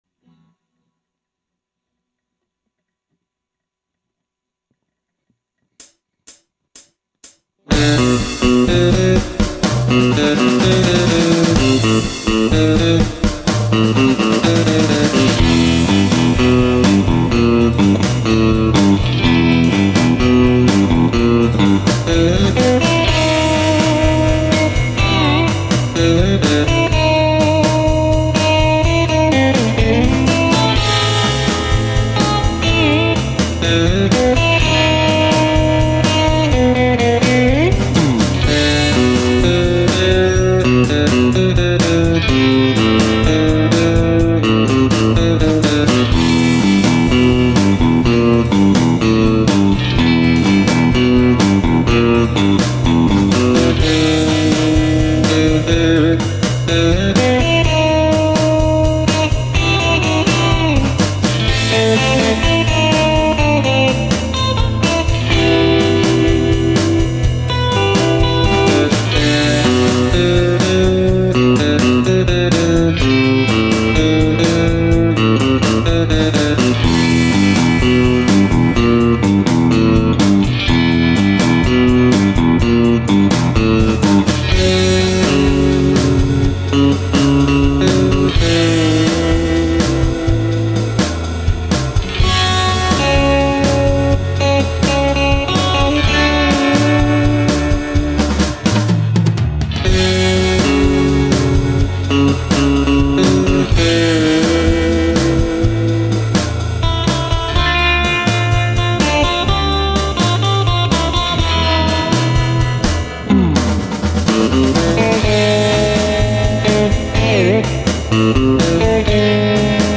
It has that Jazzmaster cluck that I like.